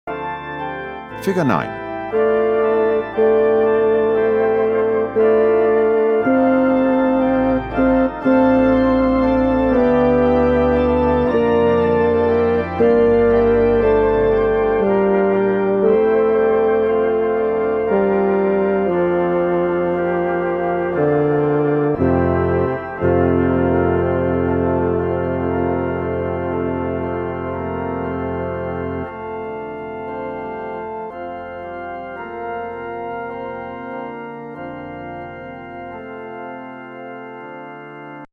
There is no text, just your part.
Introit- begins at square 9 (2nd Bass)